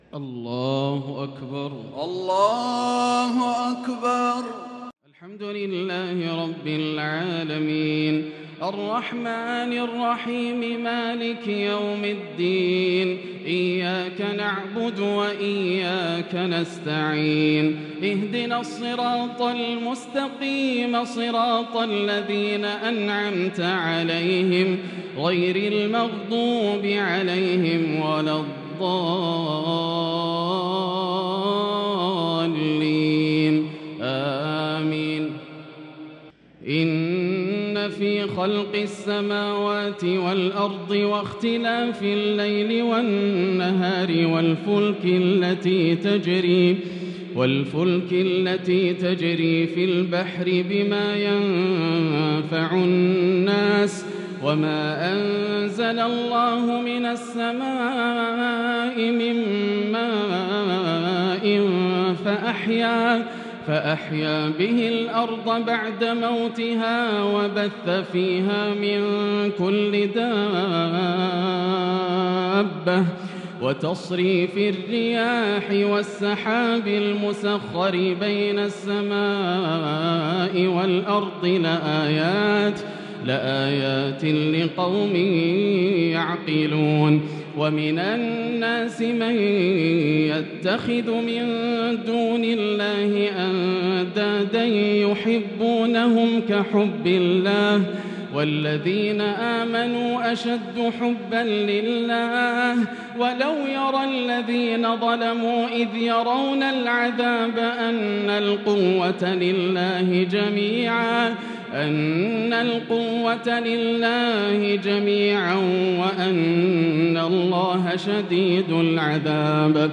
تراويح ليلة 2 رمضان 1443هـ من سورة البقرة (164-203) Taraweeh 2st night Ramadan 1443H > تراويح الحرم المكي عام 1443 🕋 > التراويح - تلاوات الحرمين